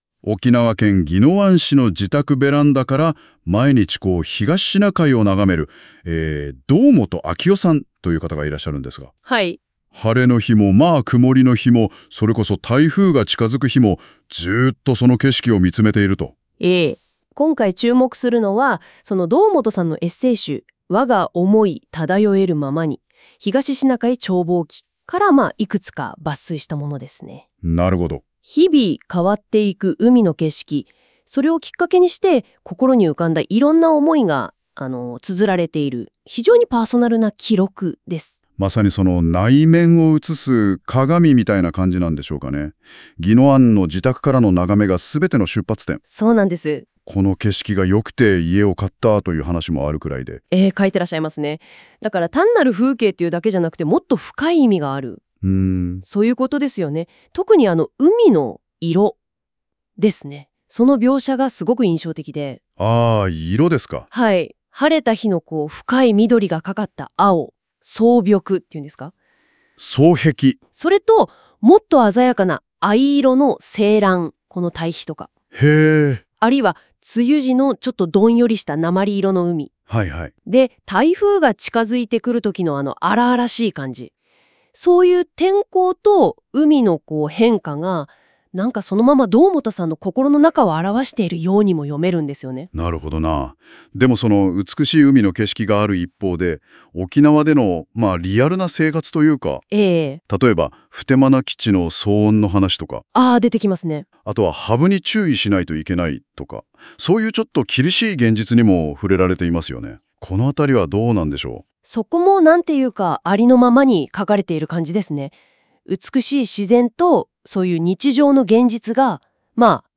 ただし、固有名詞の読み間違い等がそれなりにあり、やはり機械（AI）なのだ　なあと、独り苦笑いしています！